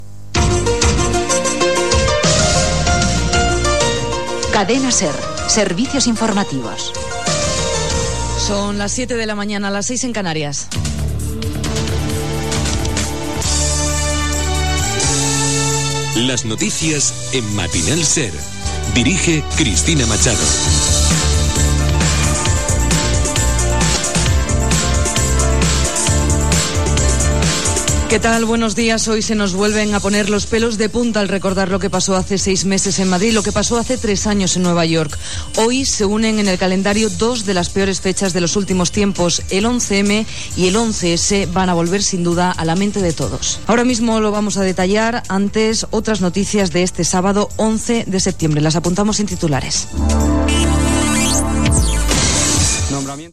Indicatiu dels serveis informatius, hora, careta del programa, record dels dies 11 M i 11 S.
Informatiu